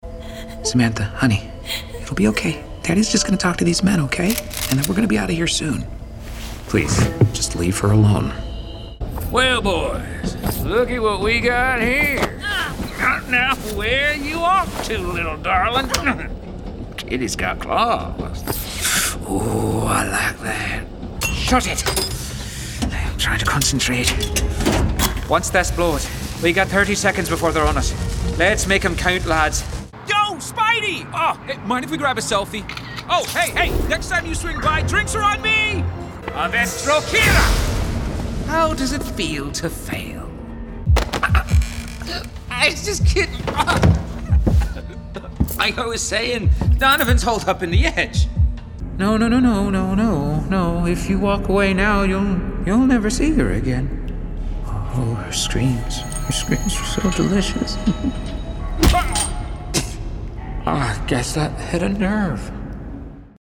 Young Adult, Adult, Mature Adult
british rp | character
standard us | natural
GAMING 🎮